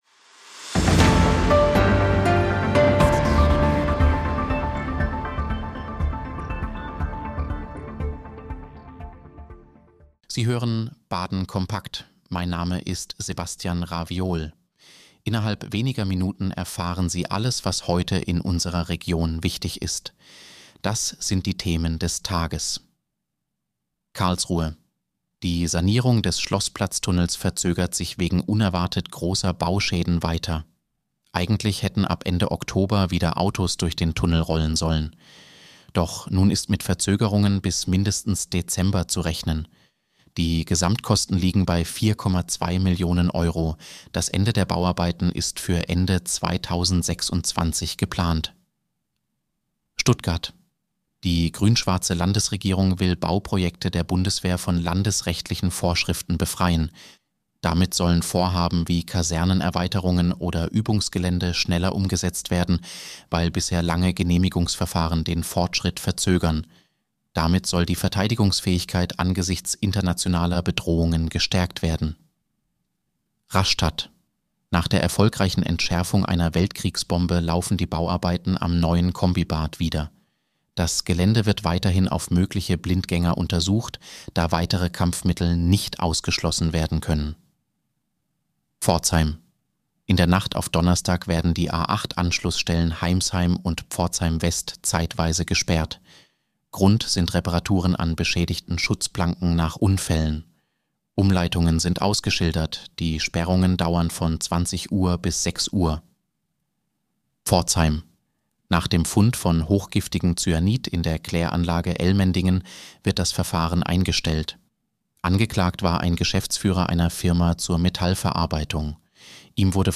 Nachrichtenüberblick Dienstag, 28. Oktober 2025
Nachrichten